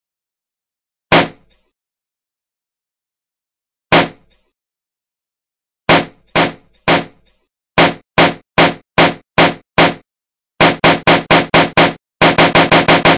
دانلود صدای ترکیدن بادکنک 2 از ساعد نیوز با لینک مستقیم و کیفیت بالا
جلوه های صوتی